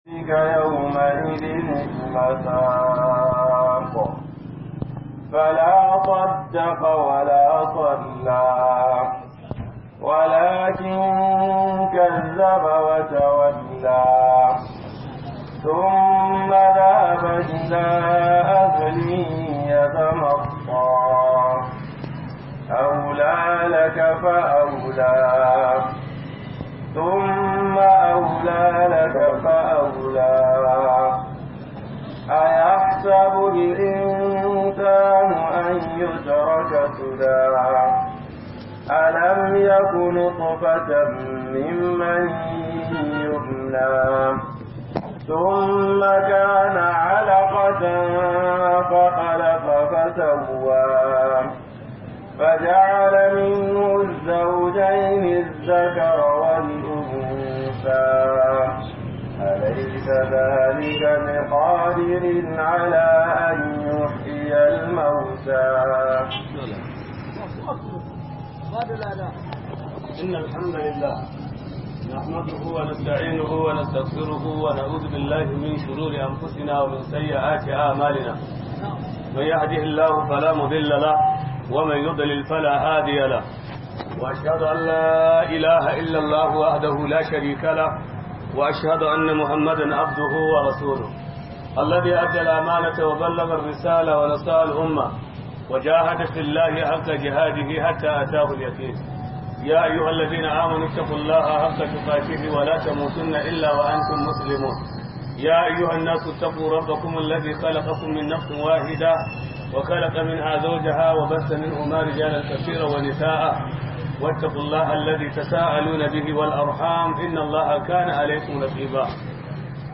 منزلة العلماء - MUHADARA